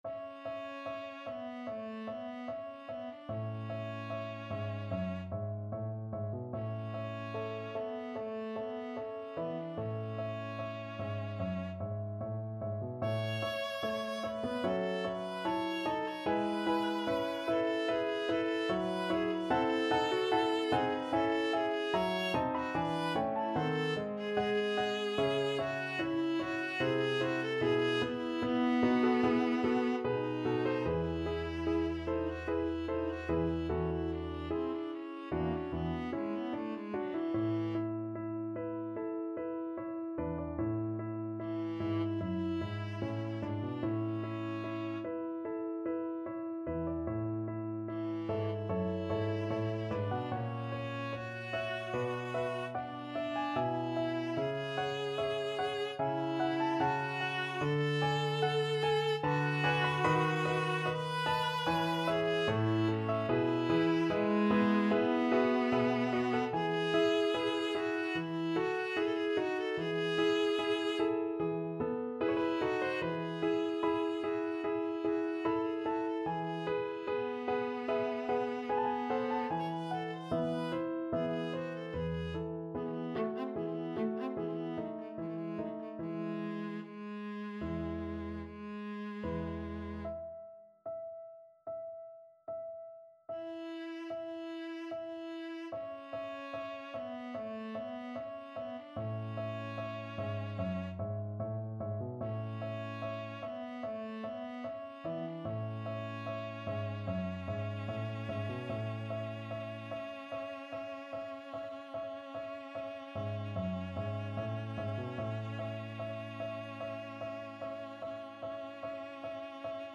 4/4 (View more 4/4 Music)
~ = 74 Moderato
Classical (View more Classical Viola Music)